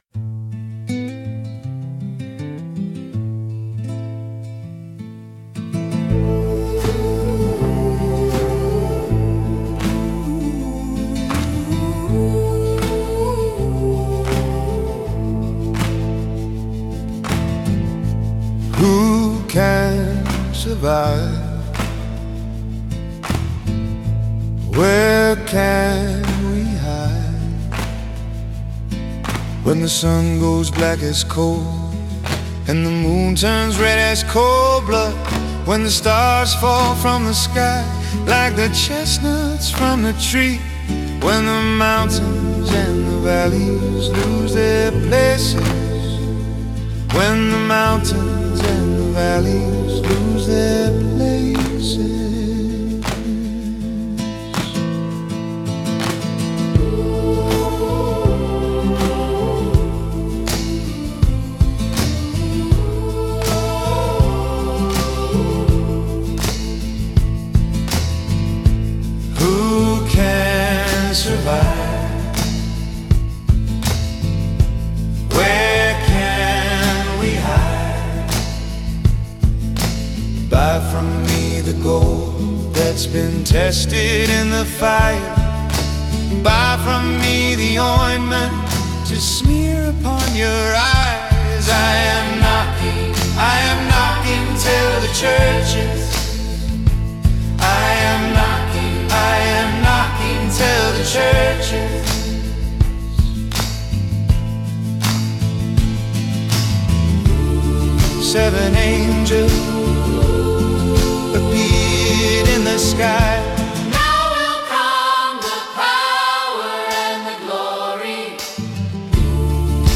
(Rock, based on Revelation 3:16)